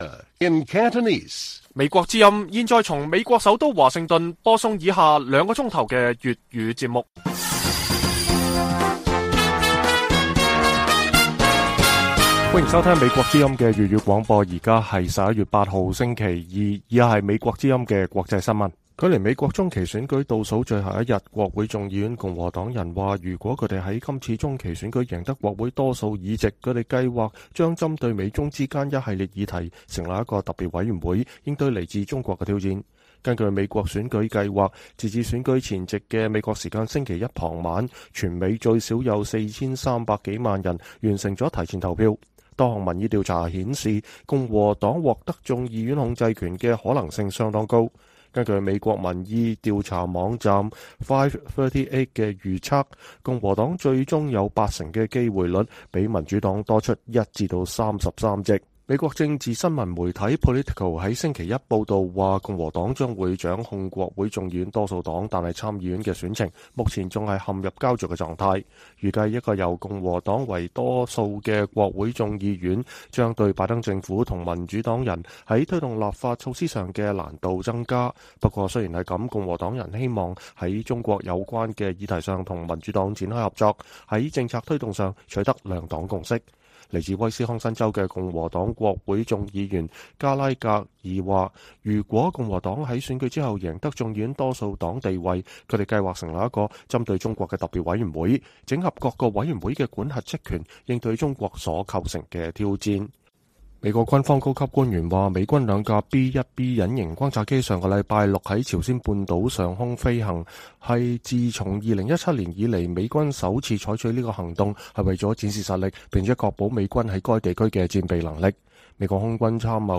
粵語新聞 晚上9-10點: 中期選舉後若贏得眾議院 國會共和黨人計劃成立中國委員會